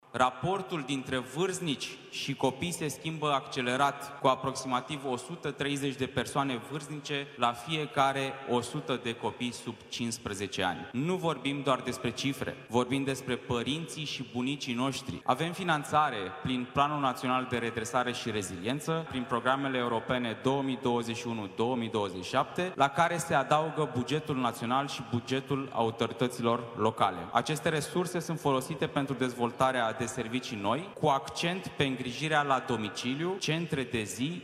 Secretarul de stat în Ministerul Muncii, Ciprian Văcaru: „Nu vorbim doar despre cifre, vorbim despre părinții și bunicii noștri”
În România, raportul dintre vârstnici și tineri continuă să crească, dar statul nu duce lipsă de finanțare pentru îngrijirea persoanelor în vârstă, a spus secretarul de stat în Ministerul Muncii, Ciprian Văcaru, la Congresul Național de Îmbătrânire Activă.